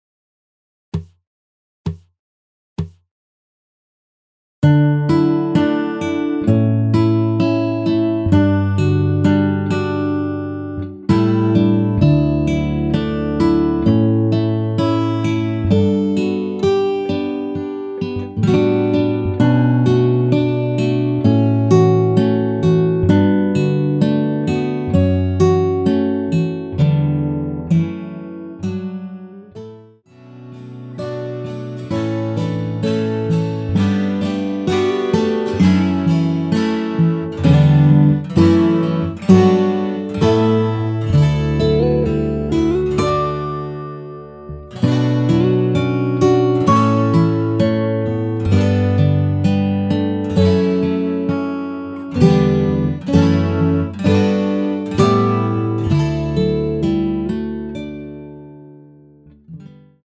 전주없이 시작 하는 곡이라 카운트 넣어 놓았습니다.(미리듣기 참조)
원키에서(-1)내린 (1절+후렴)으로 진행되는 MR입니다.
앞부분30초, 뒷부분30초씩 편집해서 올려 드리고 있습니다.